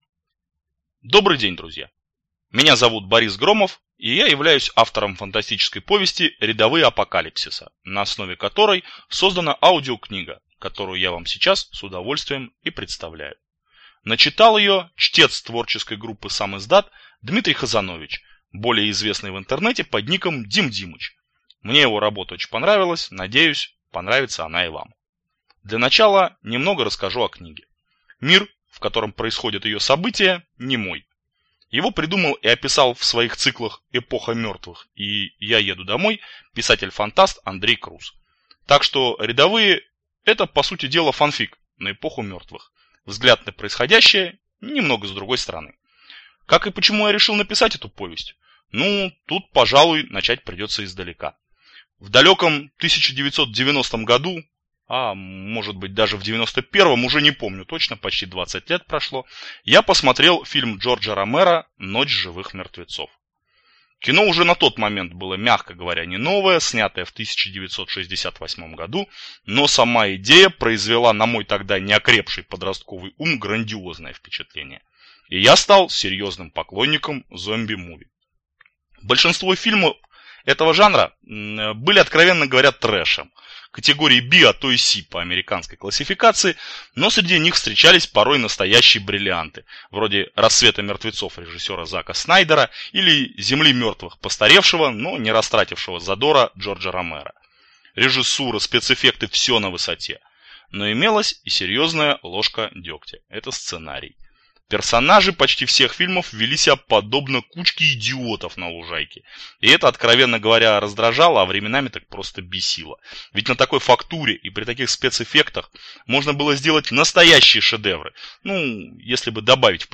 Аудиокнига Рядовые Апокалипсиса | Библиотека аудиокниг